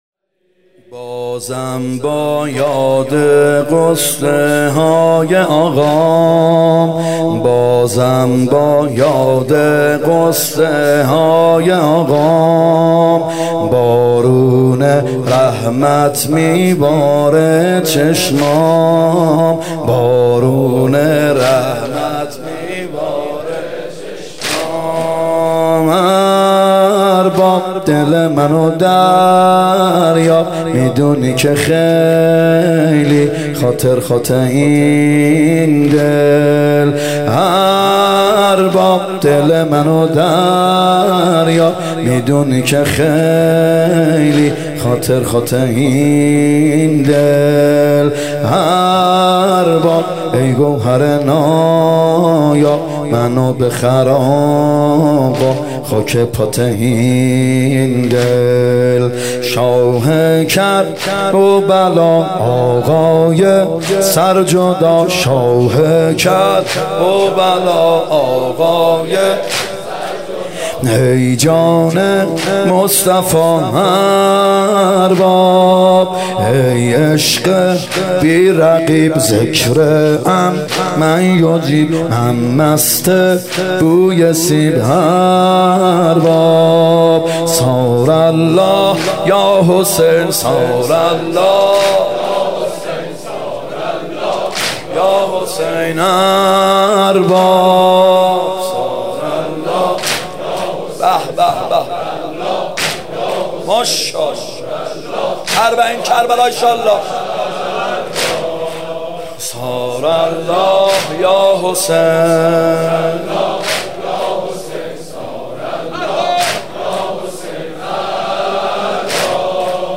محرم 95(هیات یا مهدی عج)